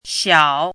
chinese-voice - 汉字语音库
xiao3.mp3